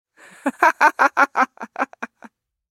Funny Yaiks Sound Effect
Funny-woman-laugh-sound-effect.mp3